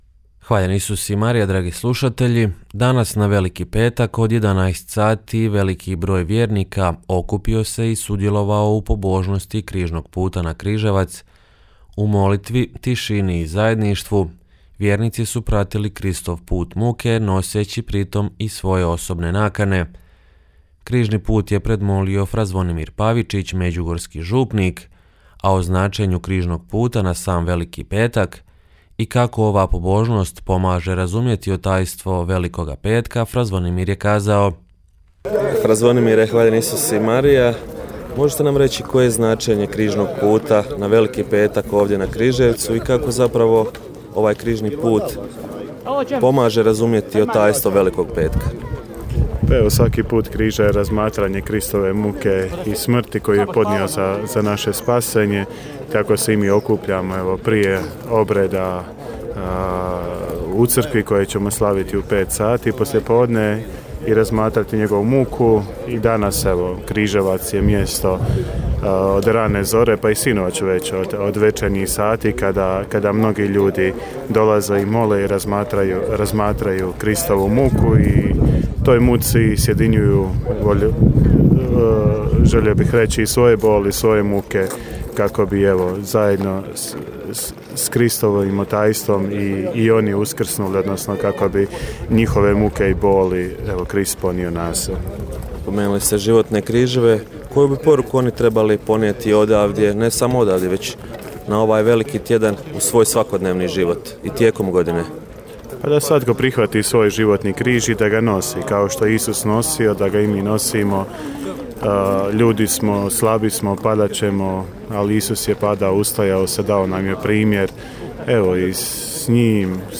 Veliki broj vjernika molio križni put uz Križevac na Veliki petak